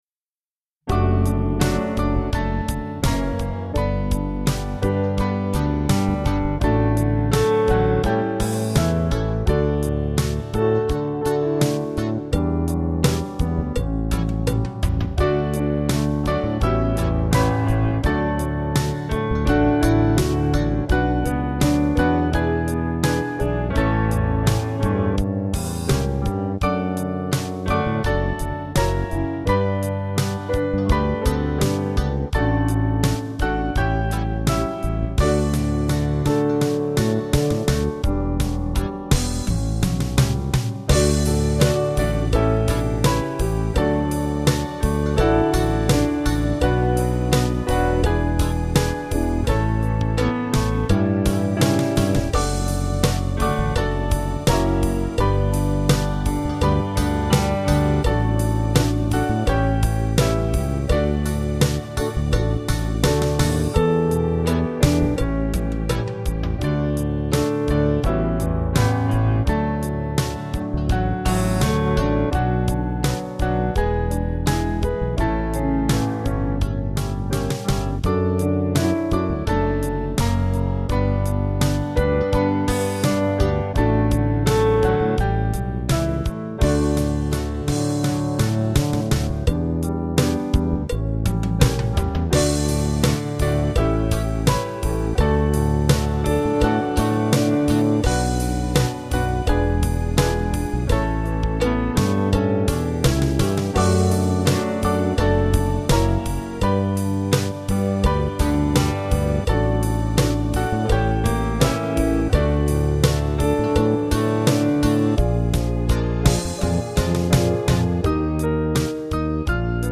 Small Band
(CM)   6/D-Eb 494.1kb